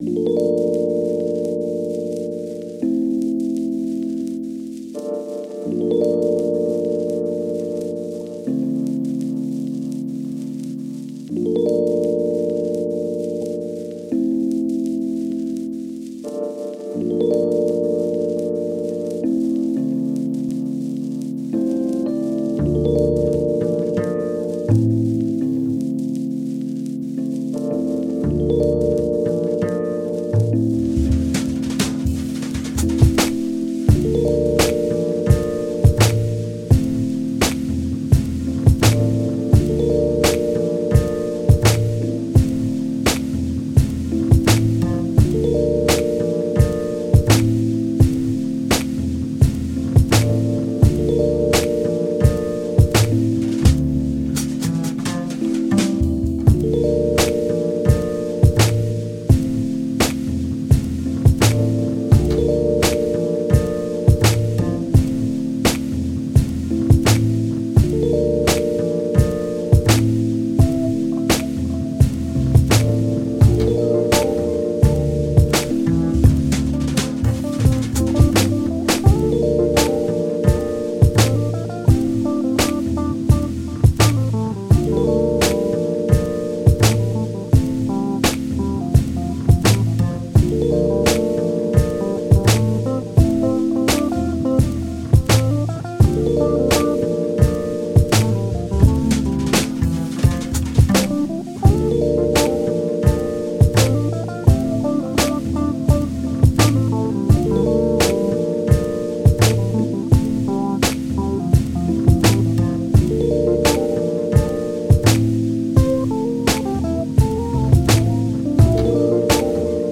Excuse My Jazz (поломанный джаз )